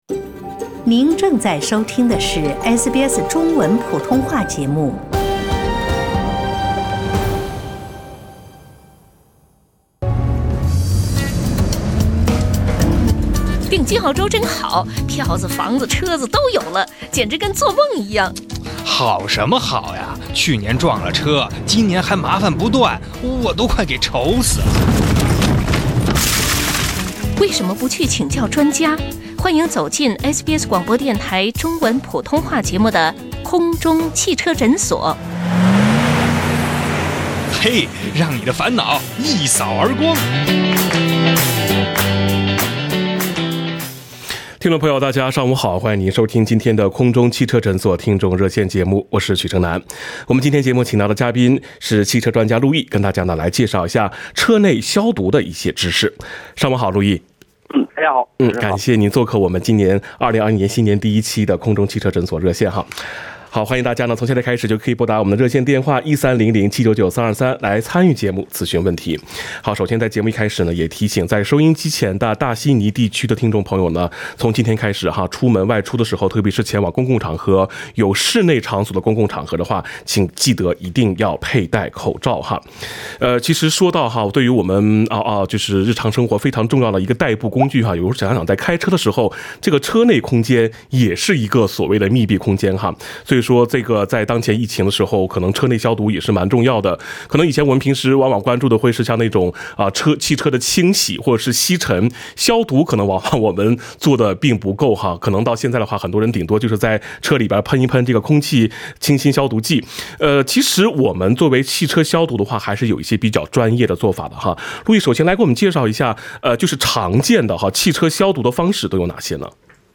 空中汽车诊所》听众热线